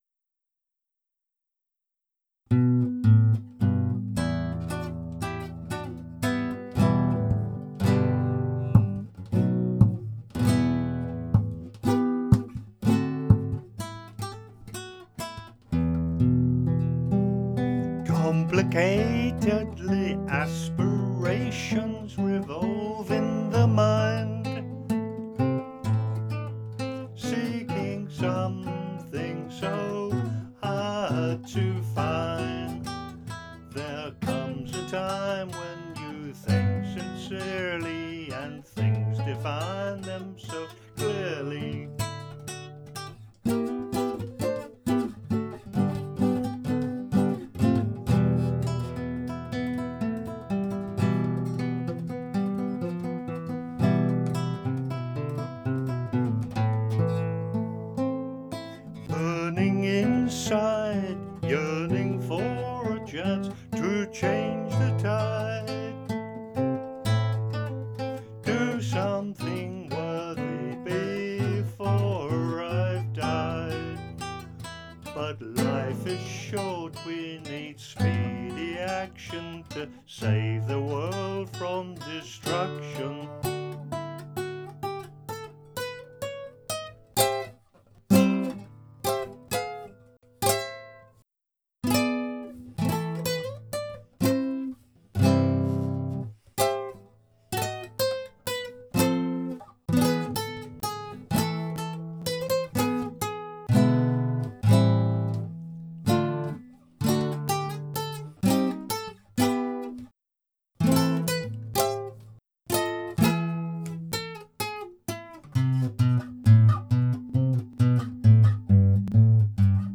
He plays and sings it here with some improvisations in challenging forms of 5/4 time.
The guitar—home-made 1964-1968